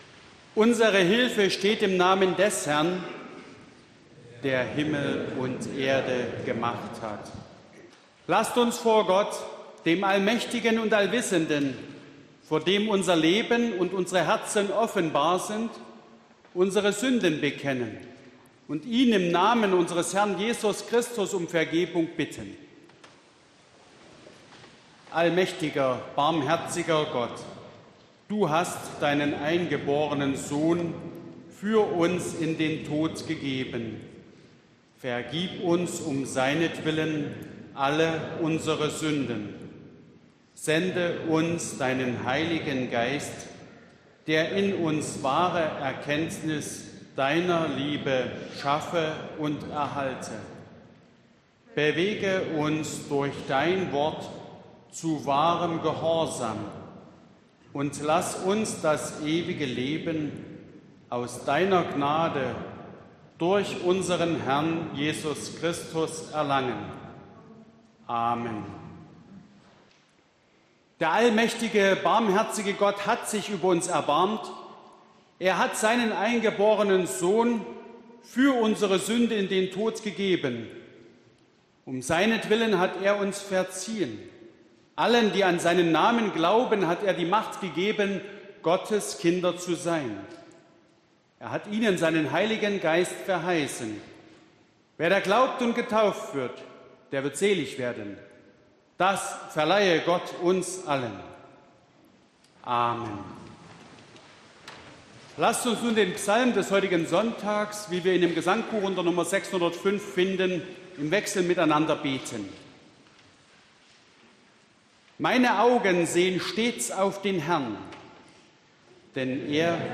Gottesdienst am 07.03.2021